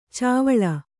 ♪ cāvaḷa